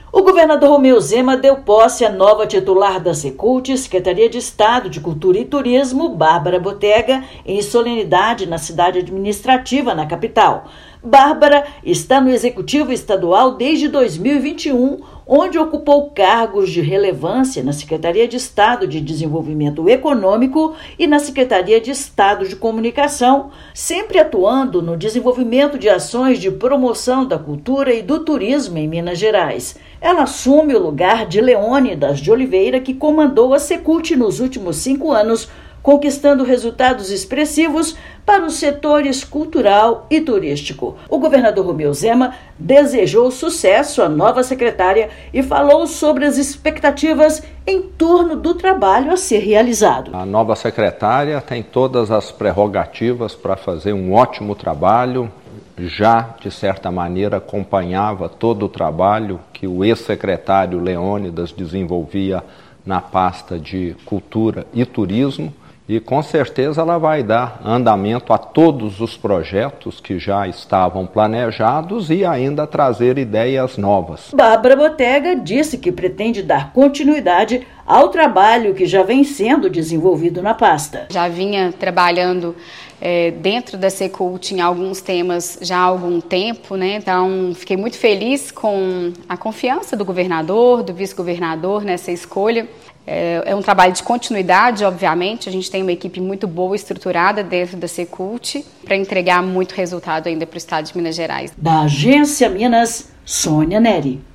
[RÁDIO] Governador de Minas empossa nova secretária de Estado de Cultura e Turismo
Bárbara Botega deixa a secretaria-adjunta de Comunicação Social para assumir o comando da Secult. Ouça matéria de rádio.